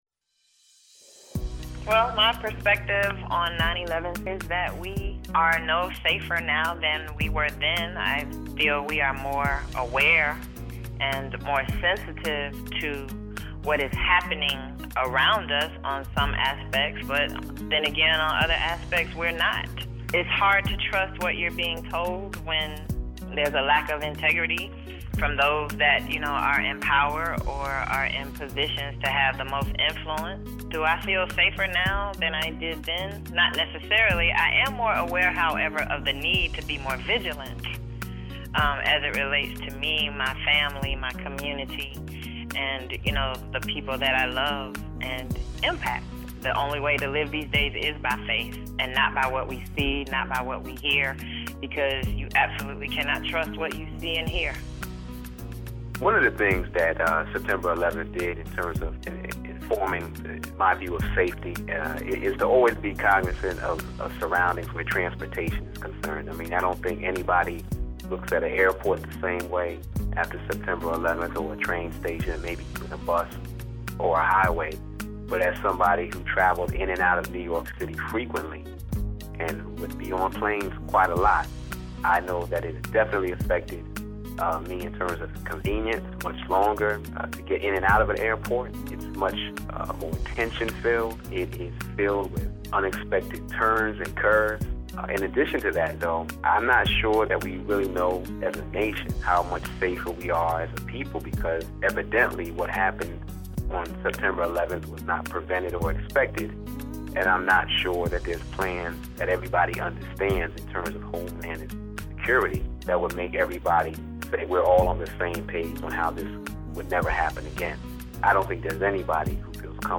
Tom Joyner rememebers 9/11 with music and audio from the events as they unfolded.